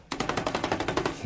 Bob Ross Brush
bob-ross-brush.mp3